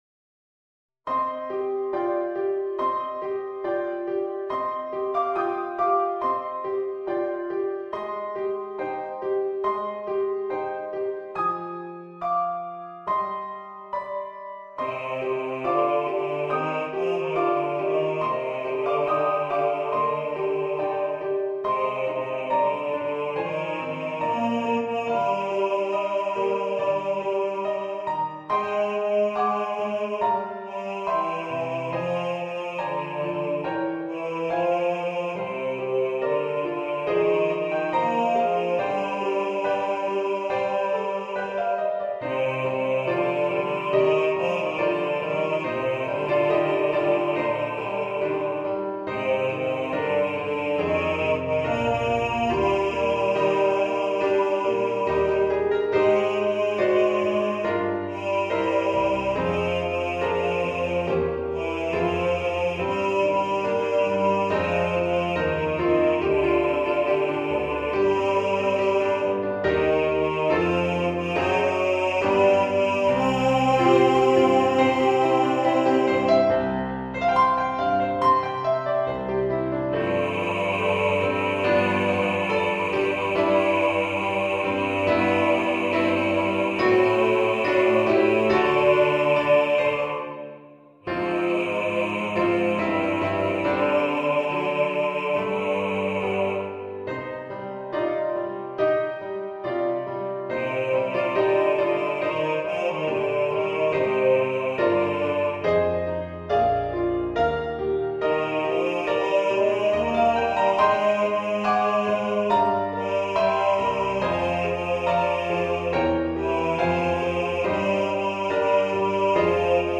Christmas Children Bass | Ipswich Hospital Community Choir